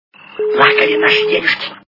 При прослушивании Приключения Буратино - Плакали наши денежки качество понижено и присутствуют гудки.